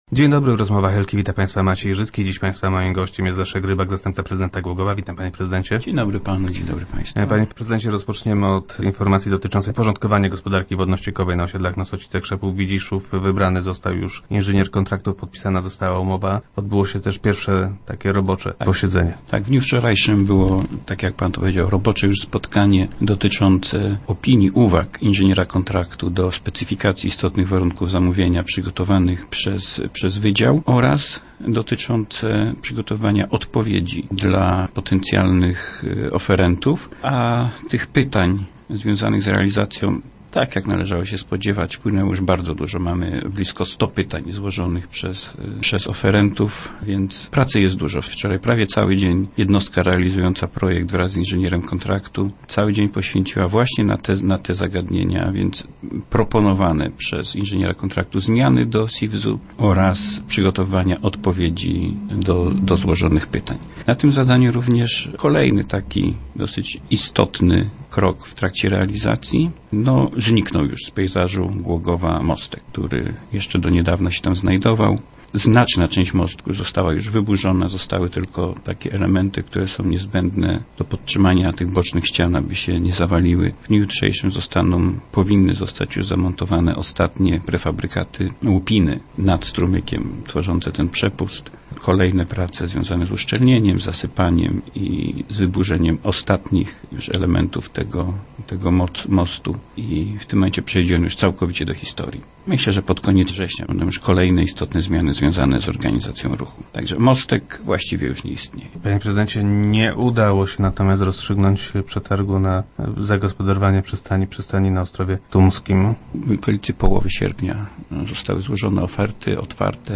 - Tam wykonawca ma duże opóźnienie w realizacji harmonogramu. W czerwcu nie było jeszcze tak źle, ale już w lipcu zaczęliśmy wzywać wykonawcę do realizacji planu. Zobowiązaliśmy go do doprowadzenia robót do obowiązującego harmonogramu. Daliśmy mu na to czas do 31 sierpnia. Niestety tak się stało. Opóźnienie jest bardzo duże - mówi wiceprezydent Rybak, który był gościem Rozmów Elki.